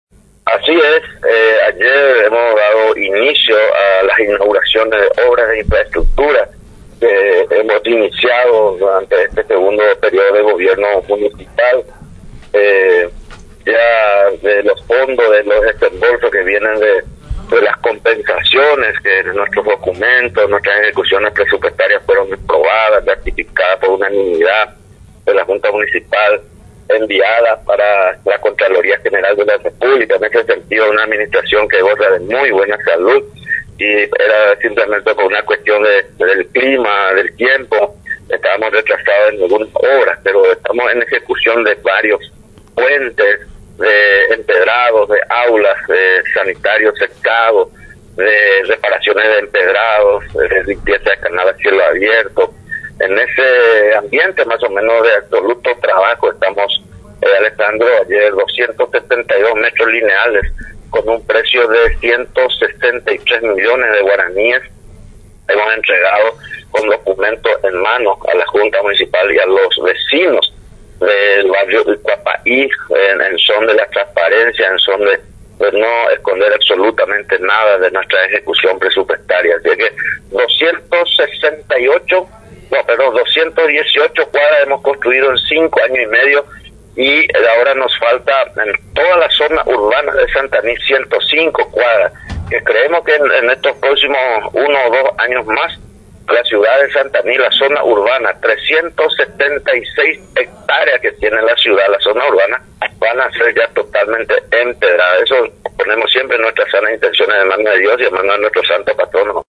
El Lic. Agustín Ovando, intendente del distrito de Santaní, en contacto con Radio Nacional San Pedro, destacó la nueva habilitación realizada por la administración a su cargo de unos 262 metros de cuadras de calles empedradas.
LIC.-AGUSTIN-OVANDO-INTENDENTE-DE-SANTANI.mp3